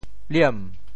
潋（瀲） 部首拼音 部首 氵 总笔划 14 部外笔划 11 普通话 liàn 潮州发音 潮州 liem2 文 中文解释 潋 <名> 水际,水边 [waterside] 华莲烂于渌沼,青蕃蔚乎翠潋。